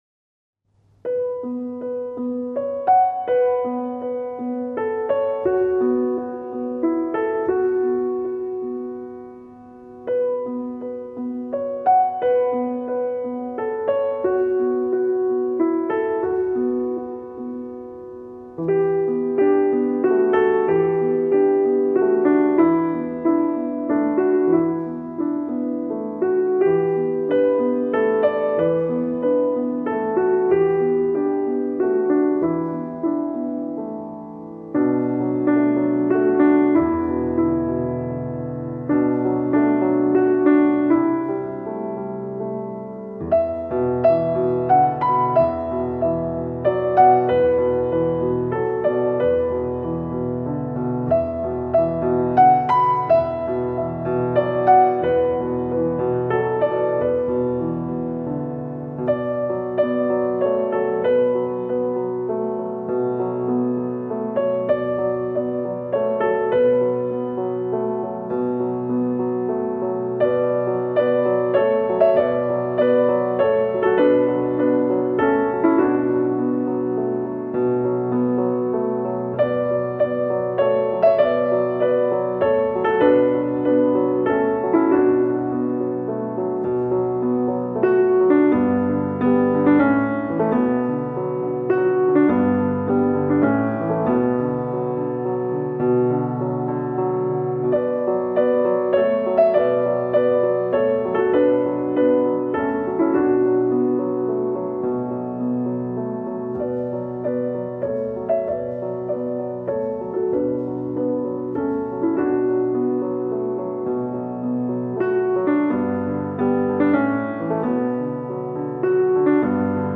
• Категория:Музыка для сна